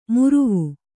♪ muruvu